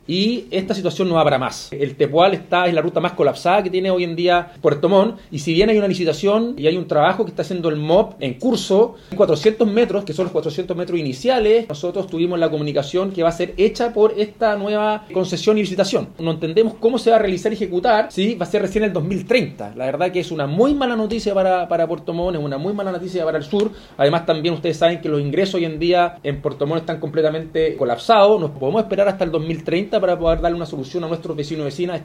Por su parte, Rodrigo Wainraihgt, alcalde de Puerto Montt, indicó que “esta situación no da para más. El Tepual es la ruta más colapsada que tiene Puerto Montt”. En ese contexto, exigió explicaciones de cómo se ejecutará en particular esa obra.